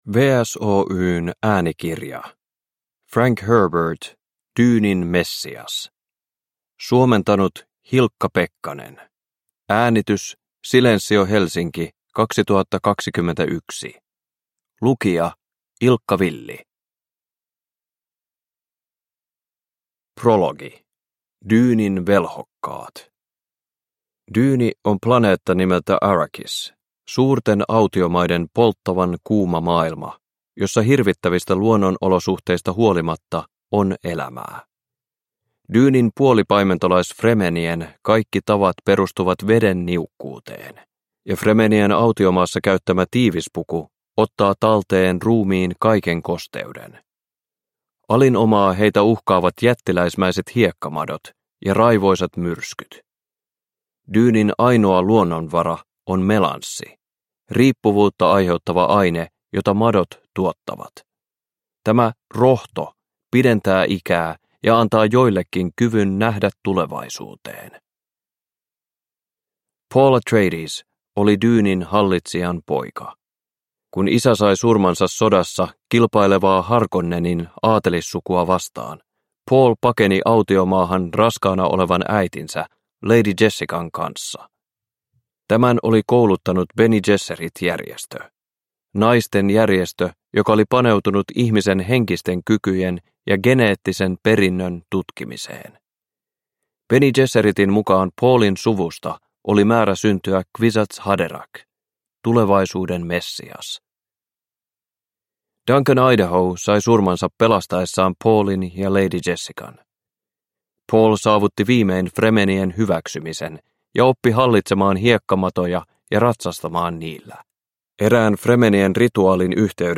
Dyynin messias – Ljudbok – Laddas ner